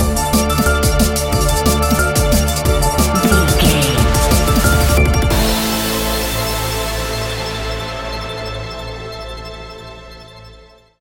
Aeolian/Minor
Fast
aggressive
dark
industrial
frantic
synthesiser
drum machine
electric piano
sub bass
synth leads